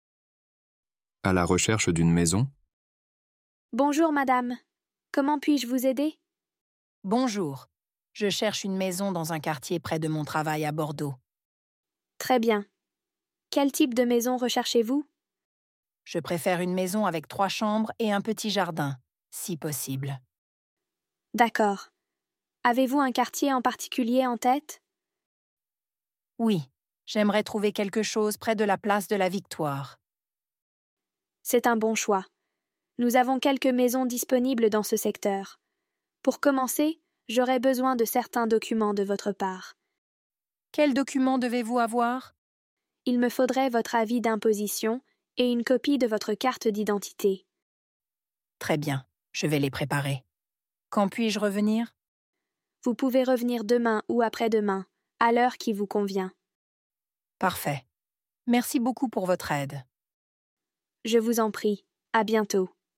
Dialogue en français A2 – Apprenez à parler de la recherche d’une maison avec ce dialogue simple entre une cliente et un agent immobilier.